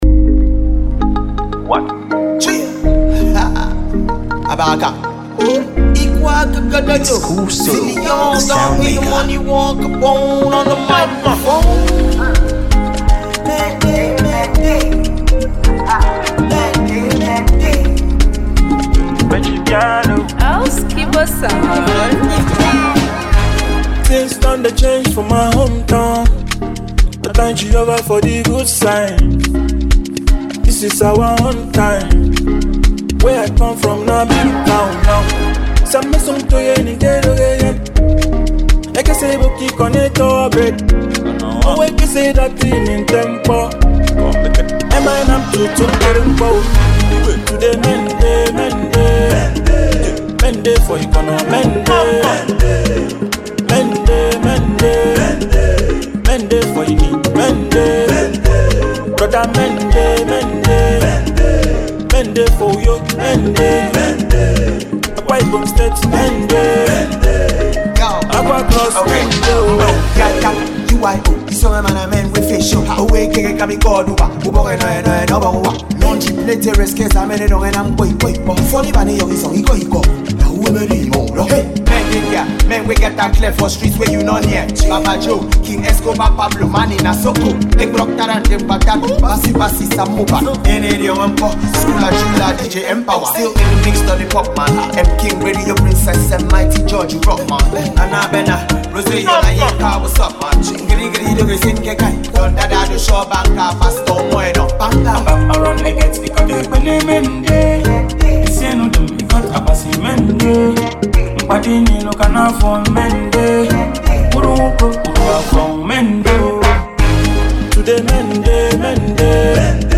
afro fushion